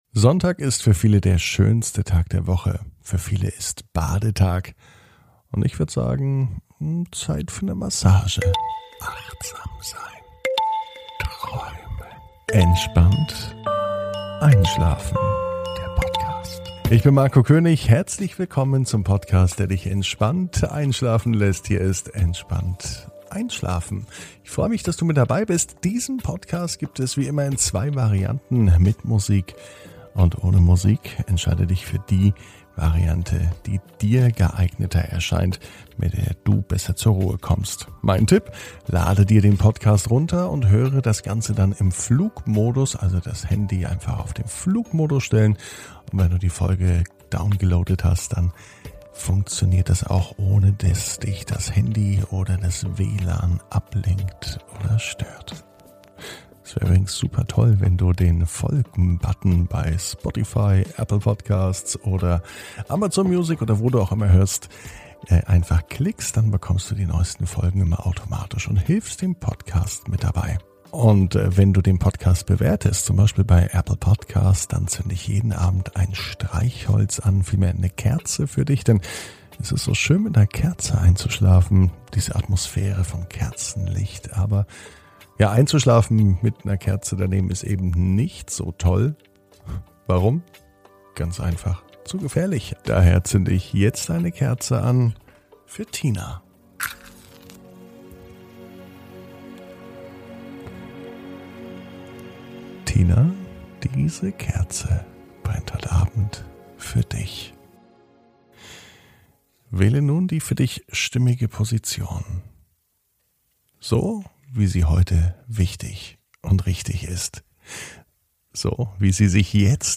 (ohne Musik) Entspannt einschlafen am Sonntag, 13.06.21 ~ Entspannt einschlafen - Meditation & Achtsamkeit für die Nacht Podcast